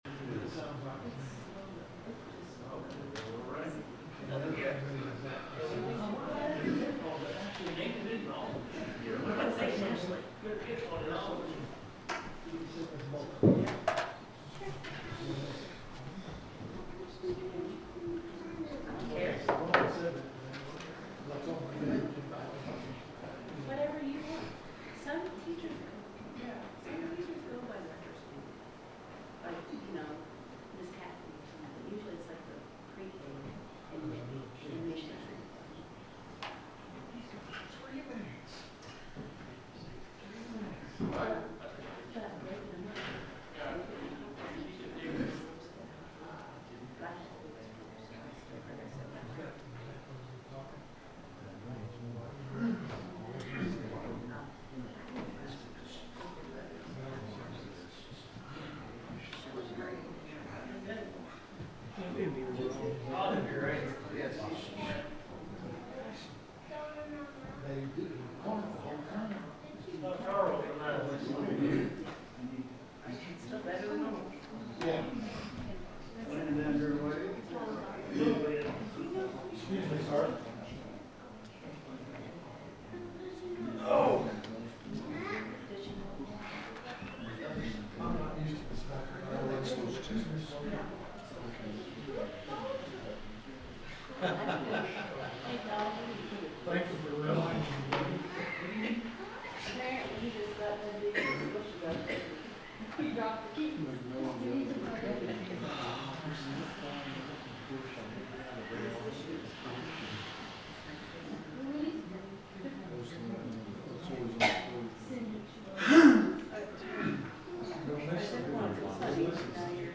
The sermon is from our live stream on 10/29/2025